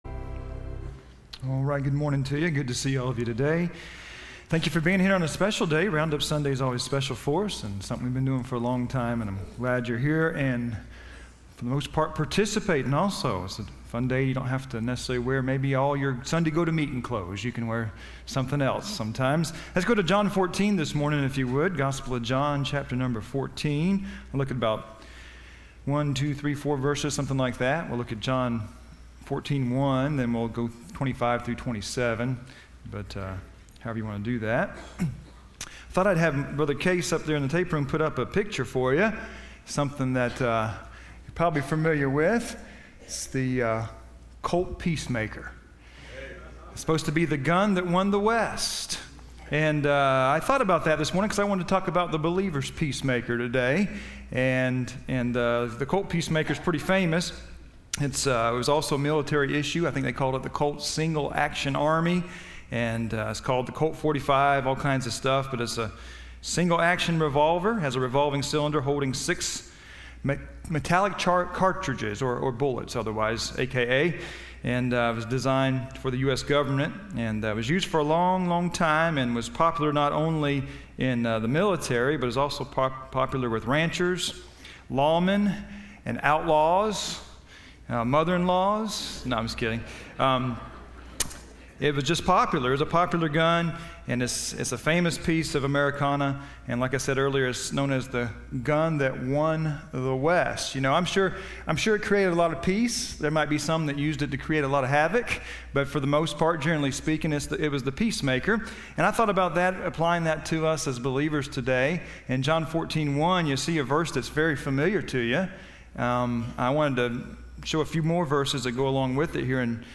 Listen to Message
Service Type: Sunday School